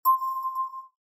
inbox_message.mp3